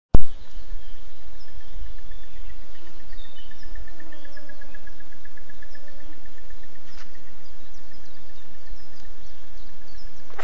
Bird Aves sp., Aves sp.
StatusVoice, calls heard
Audiofaili klusi un sliktas kvalitātes. Bet skaņa tāda kā dabā.